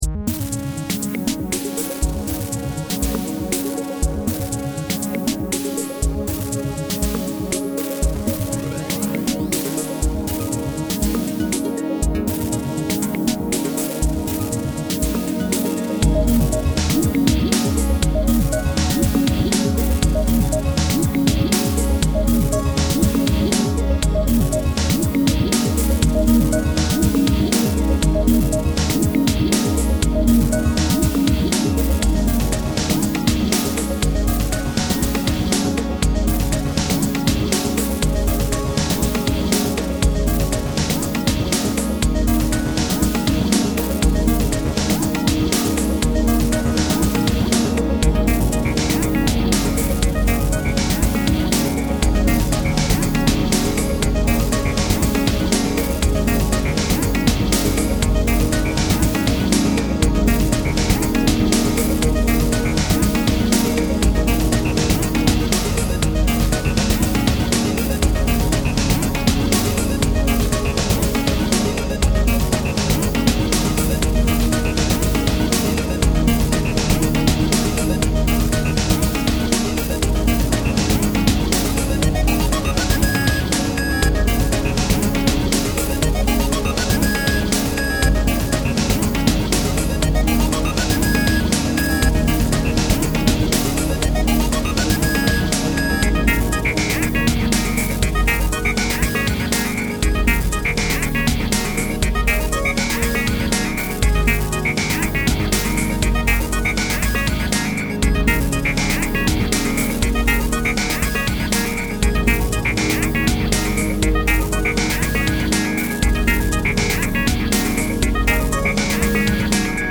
I finished an EP a while back and just accidently mixed them together - thought it sounded quite good, better than the original tracks in fact: